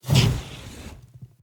Free Fantasy SFX Pack
Firebuff 1.ogg